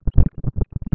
Conferencia de Prensa del Sr. VicePresidente en ejercicio de la
Montevideo. 16 de  mayo del  2002 .